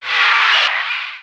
naga_mage_attack.wav